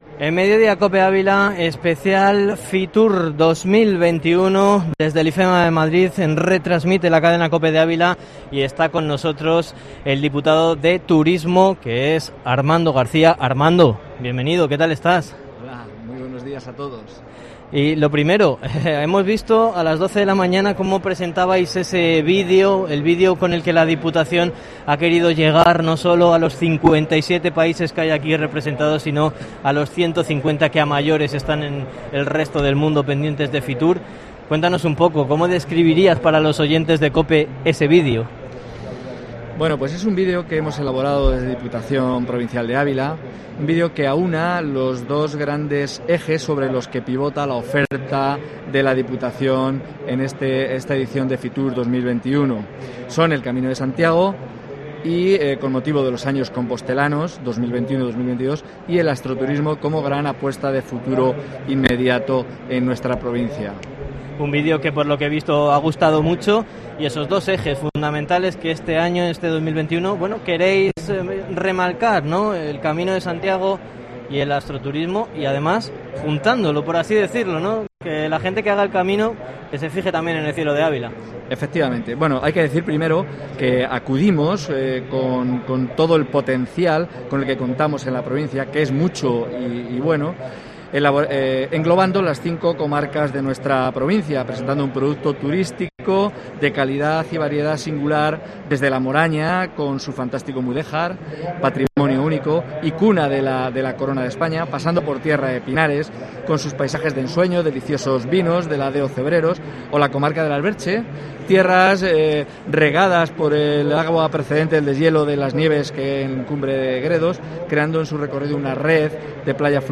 Entrevista con Armando Garcia en FITUR